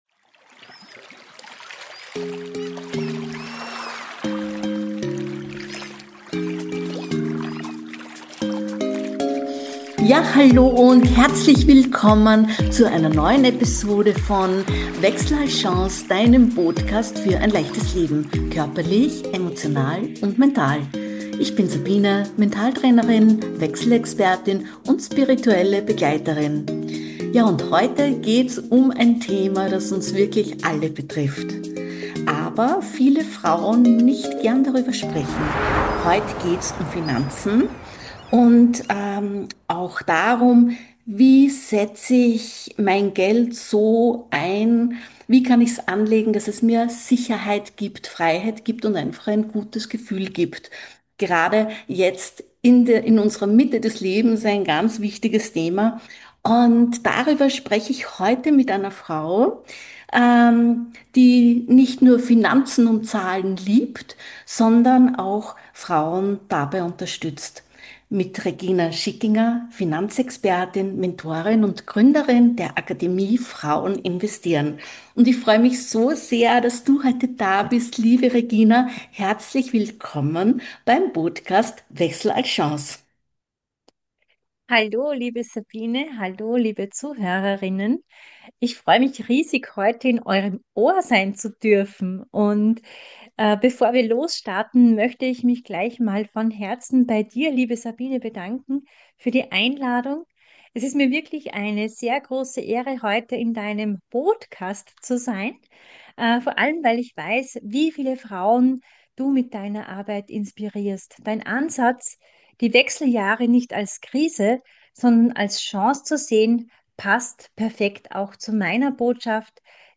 Wir nehmen dich mit in ein Gespräch, das dich entlastet, ermutigt und ins Tun bringt.